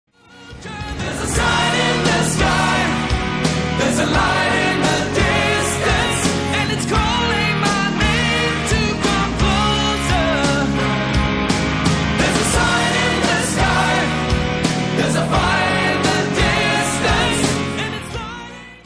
Vocals
Guitar
Bass
Drums